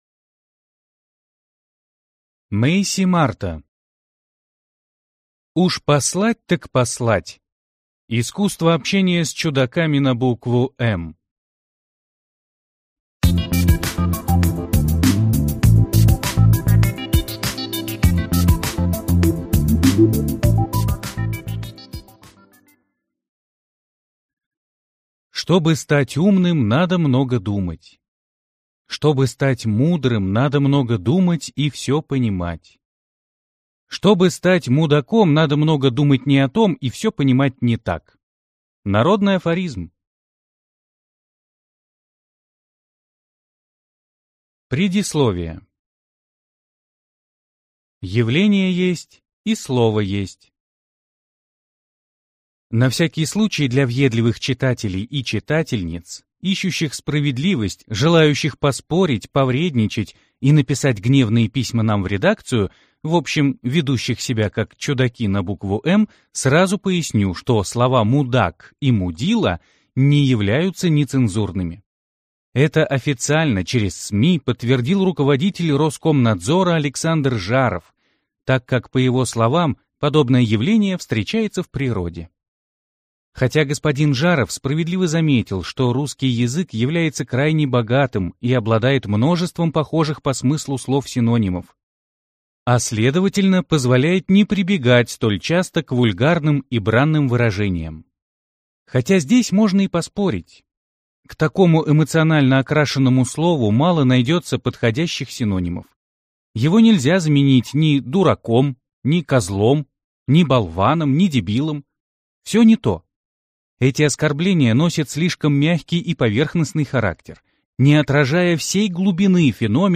Аудиокнига Уж послать так послать. Искусство общения с чудаками на букву «М» | Библиотека аудиокниг